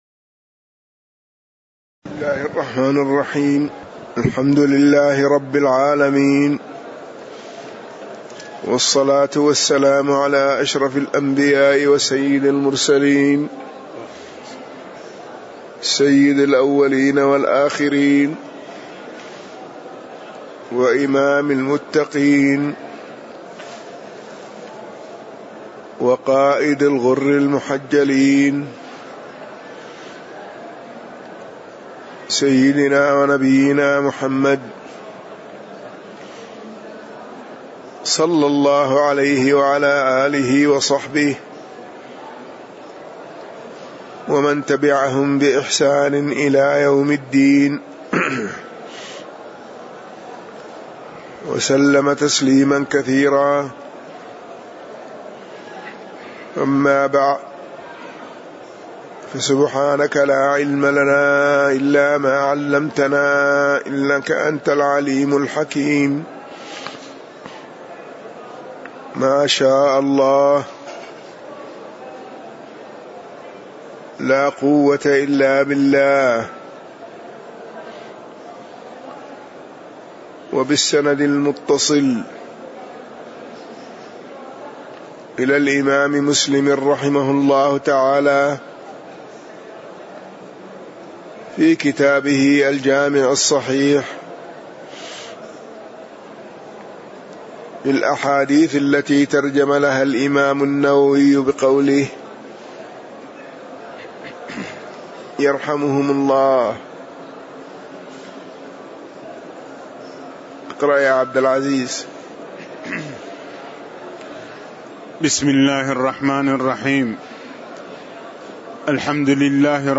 تاريخ النشر ٨ صفر ١٤٣٨ هـ المكان: المسجد النبوي الشيخ